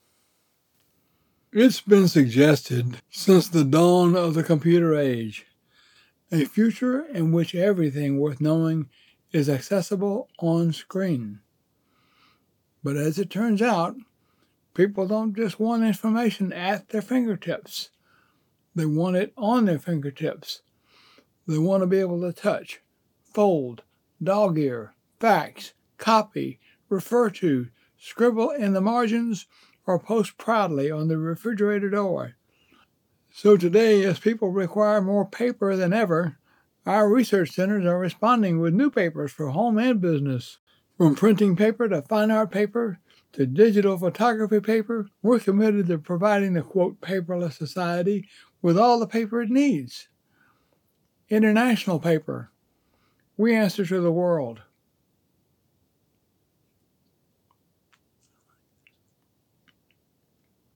American Southern senior citizen looking to voice some projects fit for him
English - Southern U.S. English